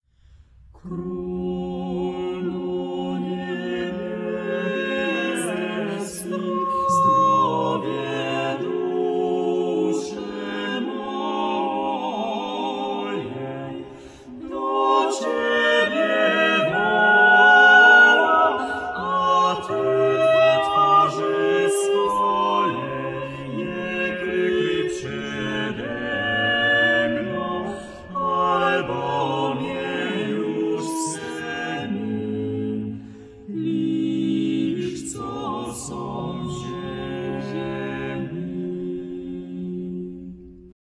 sopran
fidel
harfa, bęben, śpiew
lira korbowa, psałterium, bęben, viola da gamba
puzon
kontratenor
tenor
baryton